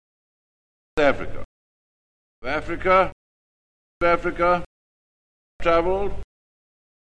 All the recordings have been subjected to lossy MP3 compression at some time during their lives.